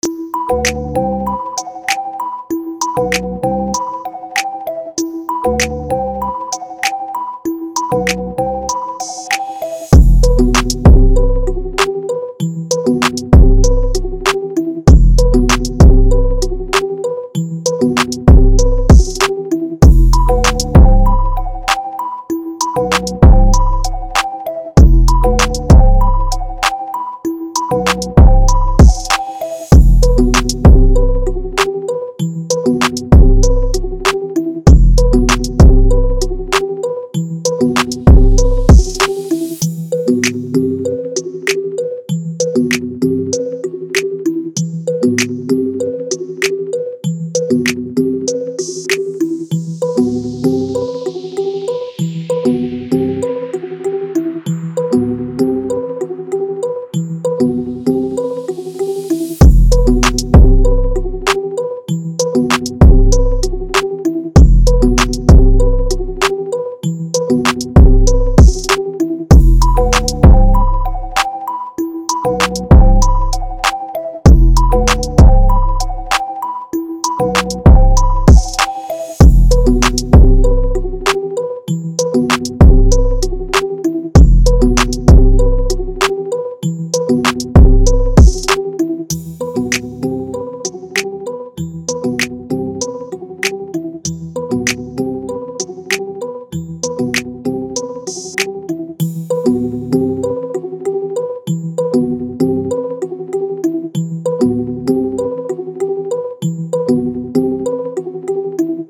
R&B
E minor